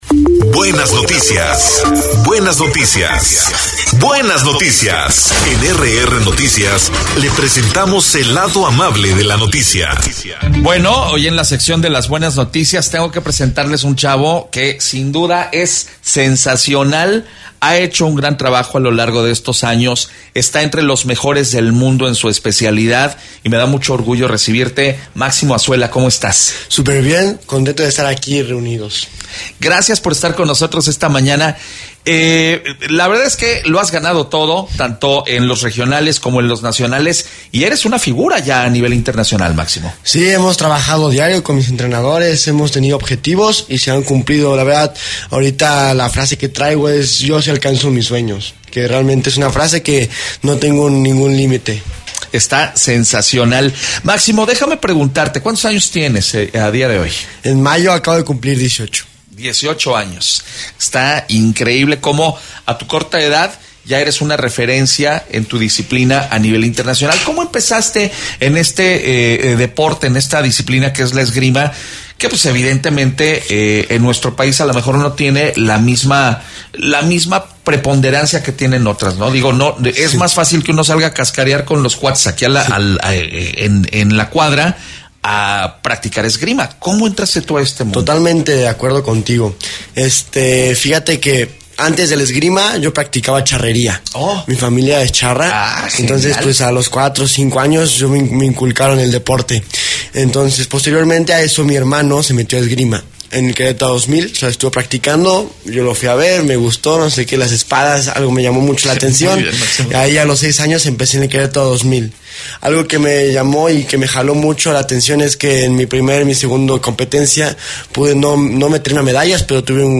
EntrevistasOpinión